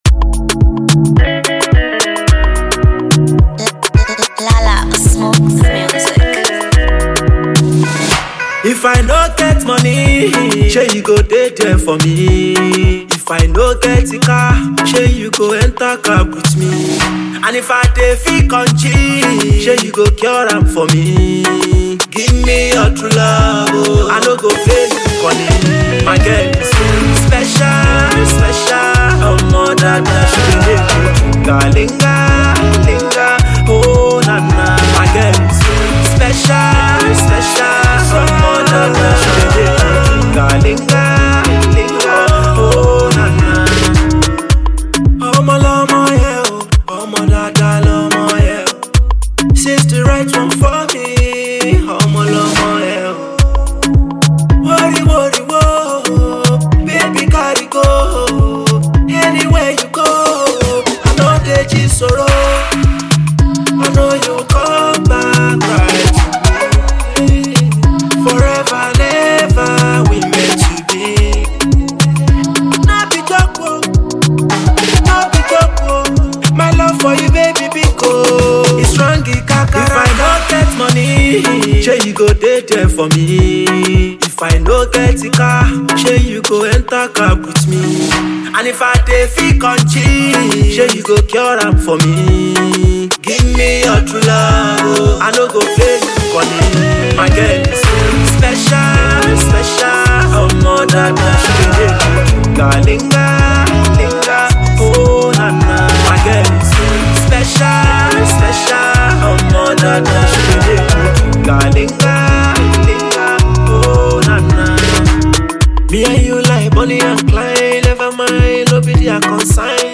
Super gifted afrobeat singer